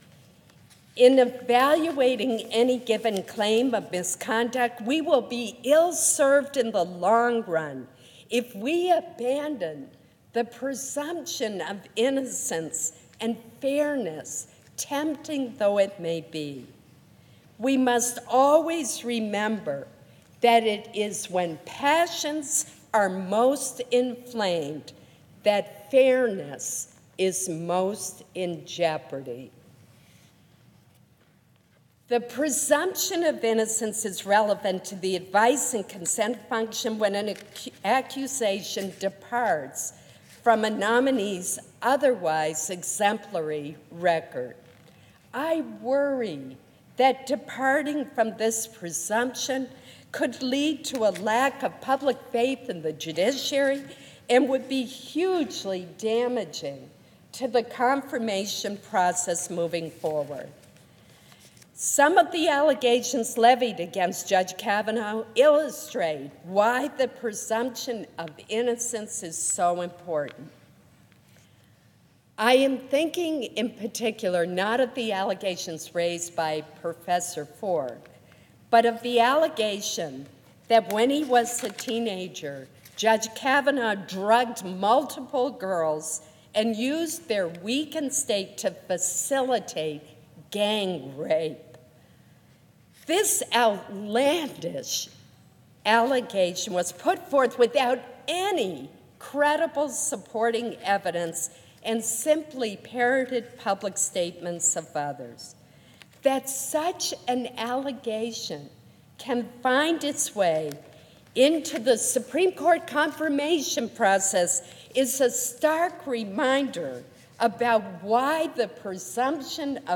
A Well Reasoned Speech
Sen. Susan Collins, speaking on the Senate floor, October 5, 2018
susan-collins-clip.mp3